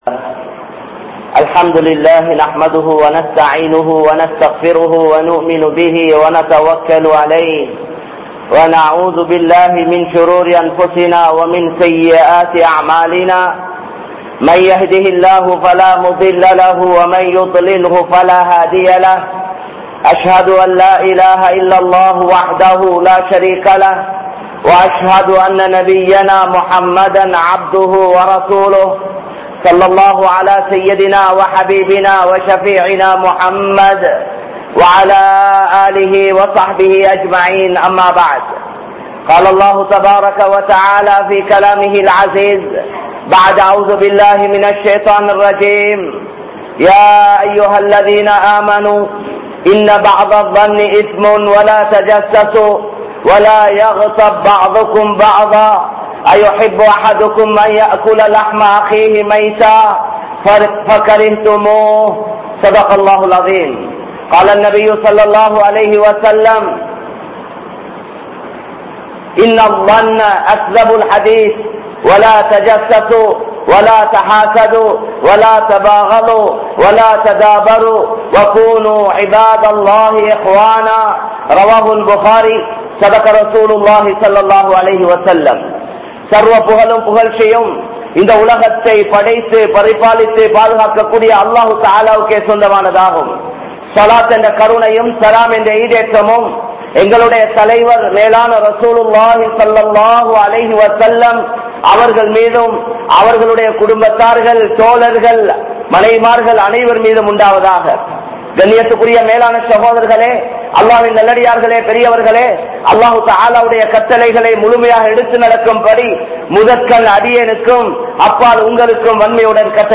Nimbathiyaana Vaalkai Veanduma? | Audio Bayans | All Ceylon Muslim Youth Community | Addalaichenai
Samman Kottu Jumua Masjith (Red Masjith)